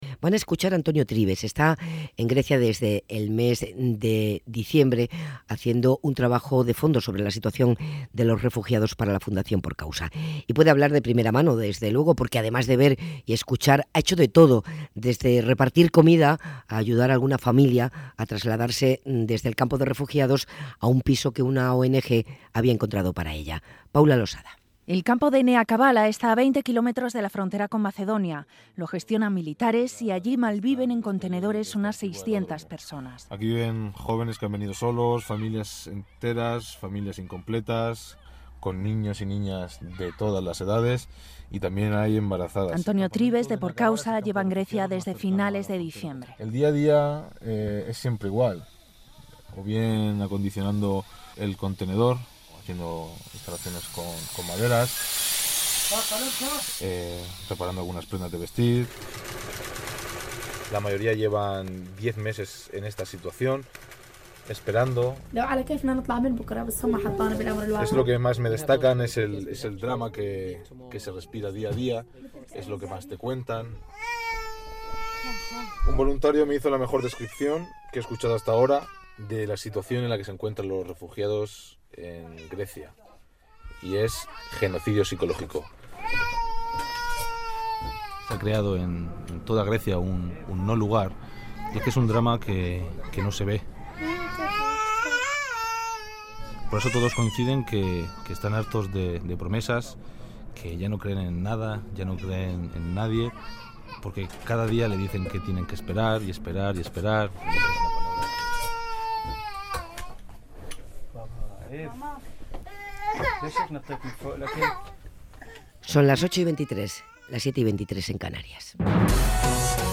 Crónica desde campamento de refugiados en Grecia_Cadena SER